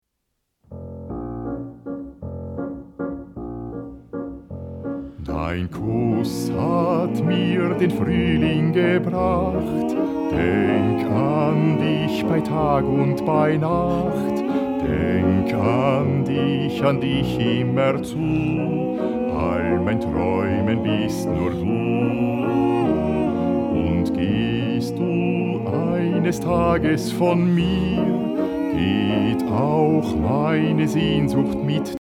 Genre: Schlager.